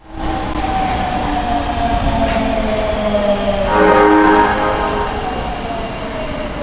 〜車両の音〜
30000系警笛
大阪市営地下鉄伝統の音。発車の際にほぼ必ず鳴らされます。